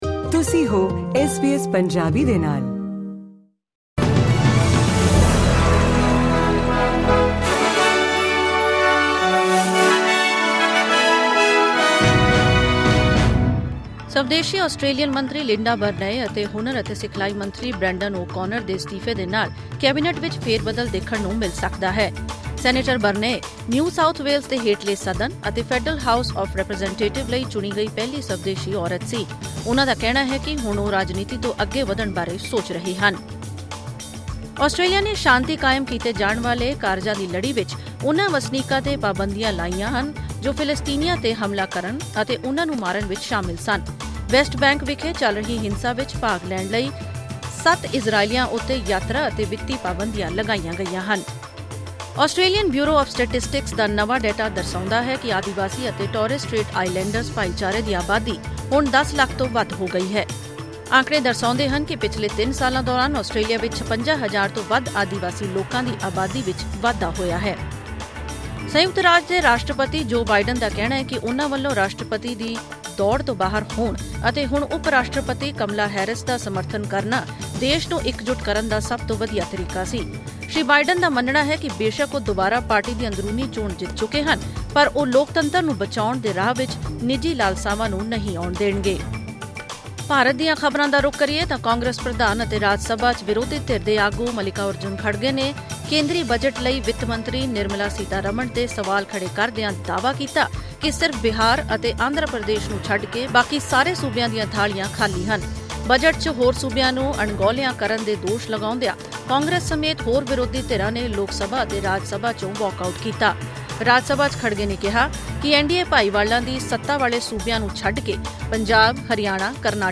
ਐਸ ਬੀ ਐਸ ਪੰਜਾਬੀ ਤੋਂ ਆਸਟ੍ਰੇਲੀਆ ਦੀਆਂ ਮੁੱਖ ਖ਼ਬਰਾਂ: 25 ਜੁਲਾਈ 2024